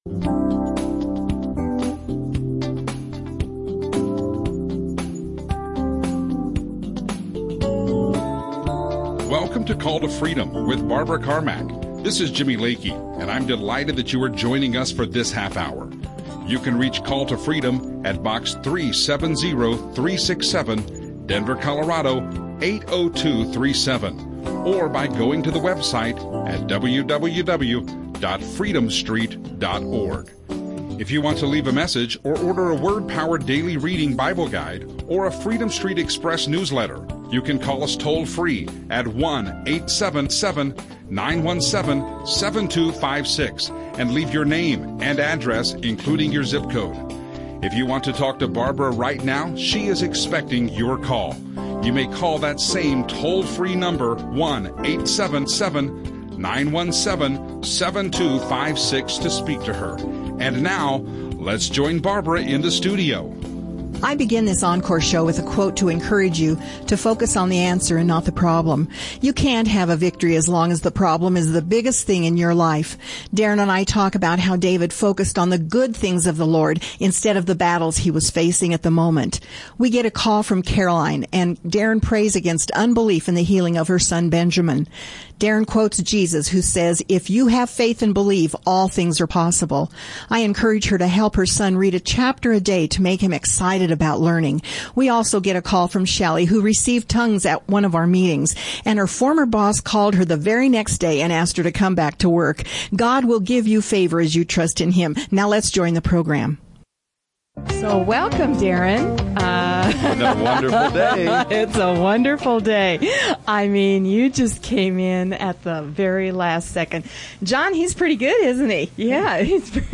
Christian radio show live radio show